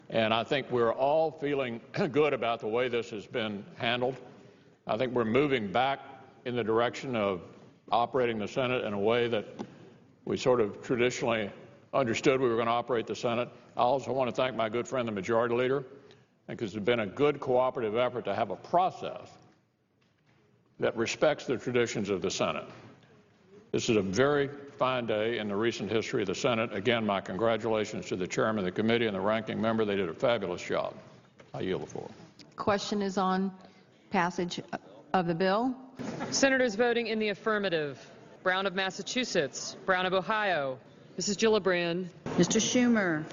here to listen to an audio clip (from C-Span video) of local Senators voting on the Farm Bill, with Republican Minority Leader Mitch McConnell, who voted against the Farm Bill, explaining the law was crafted in a bi-partisan fashion.